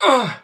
default_hurt3.ogg